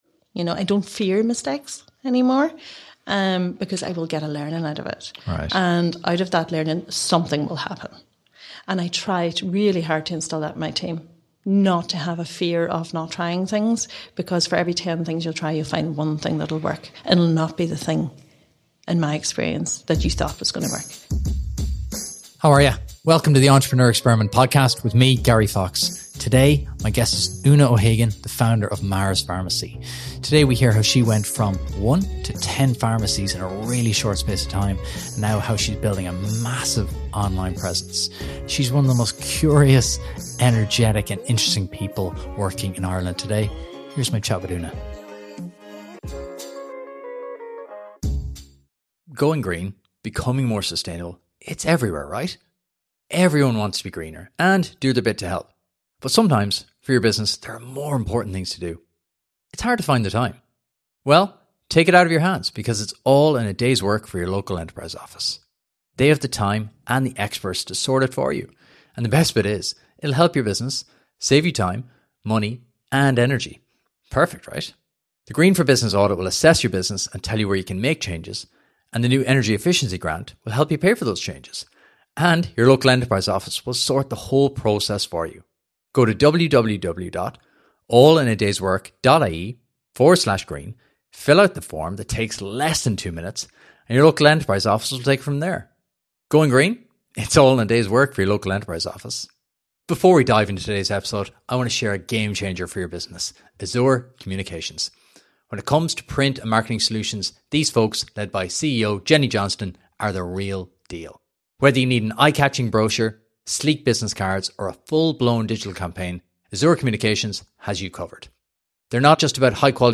Packed with insights on scaling a business and adapting to change, this conversation is a must-listen for aspiring entrepreneurs and business leaders alike.